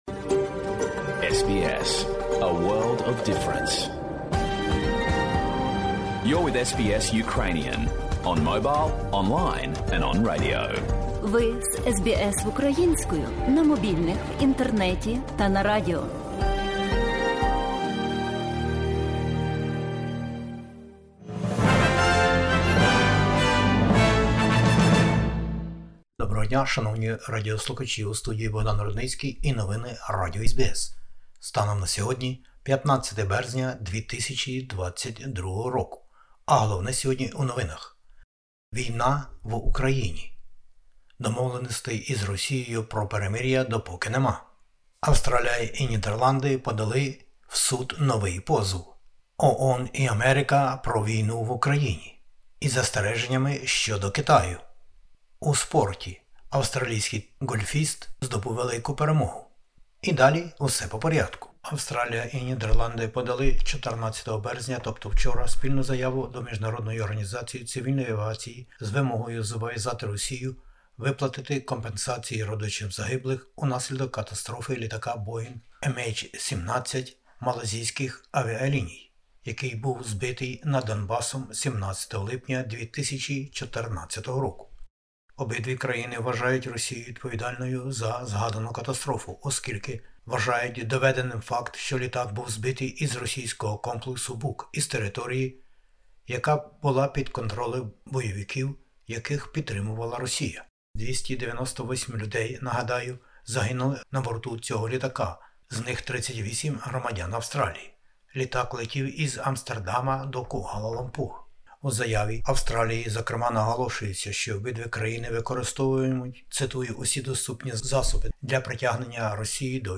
Бюлетень новин SBS українською мовою. Нині у новинах SBS Ukrainian: війна в Україні, перемовини України і Росії, новий позов Австралії та Нідерландів до міжнародного суду, застереження для Китаю, вибори в Австралії наближаються, зізнання російської журналістки як сповідь перед народом, перемога австралійського ґольфіста із 5-ма мільйонами доларів...